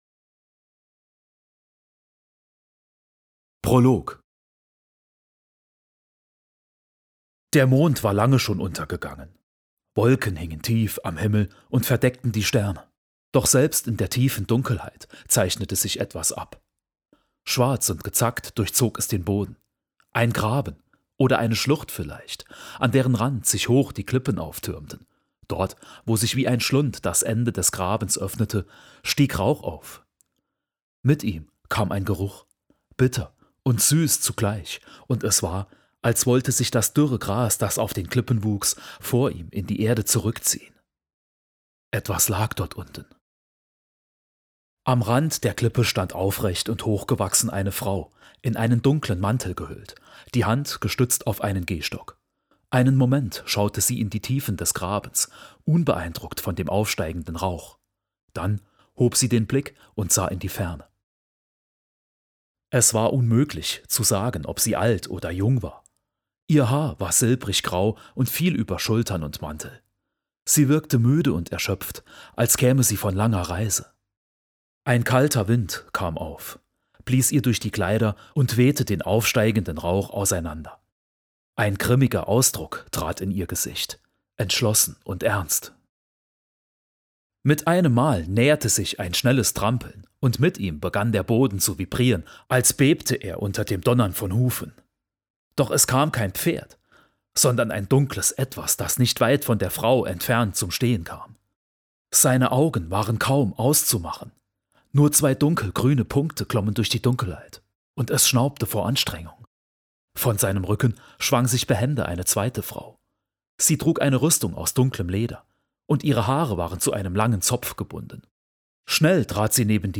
Der Untergang Ijarias – Hörbuch - Der Untergang Ijarias
Angenehme Stimme. Lässt sich gut verstehen und anhören.